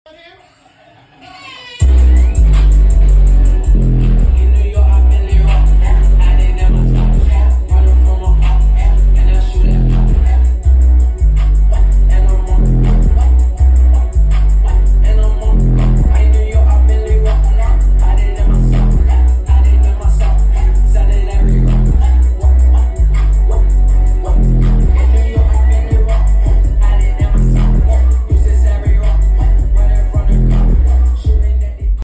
Bass Is Insane Duo JblPartyBox Sound Effects Free Download